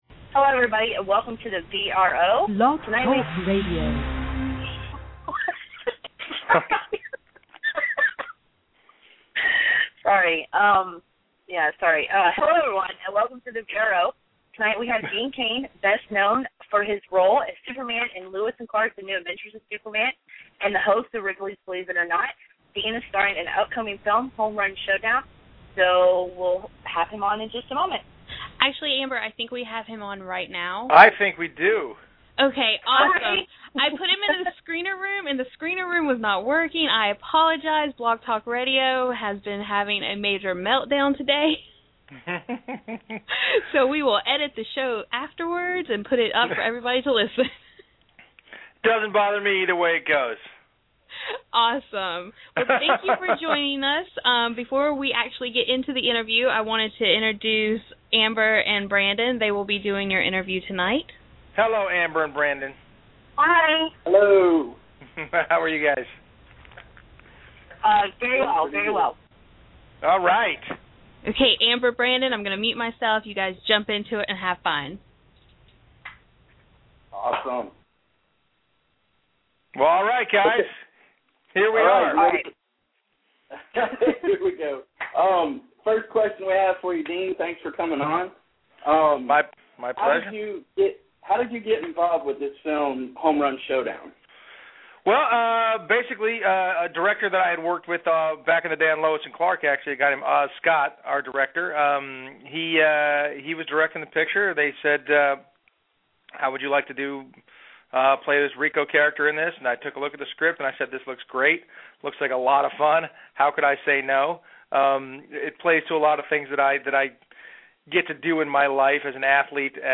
Dean Cain Interview